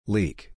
Quando as vogais e e a estiverem juntas ea, elas são pronunciadas com o som da letra “i”: leadeatreadeach